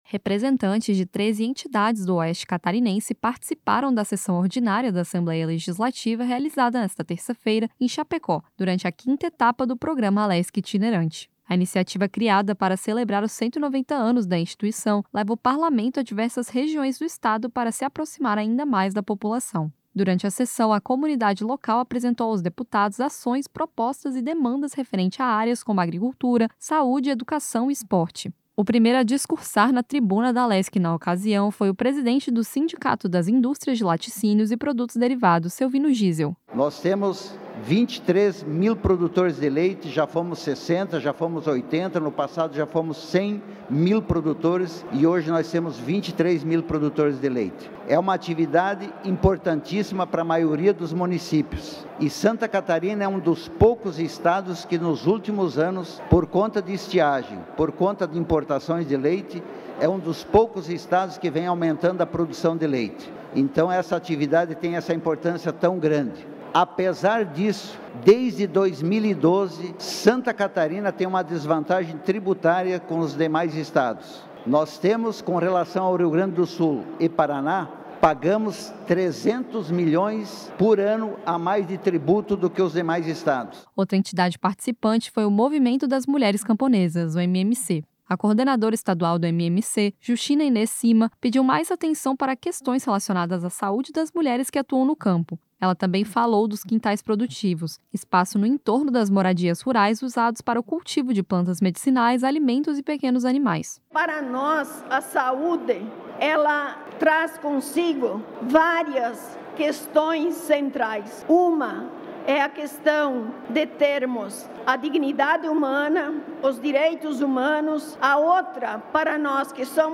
Entrevistas com: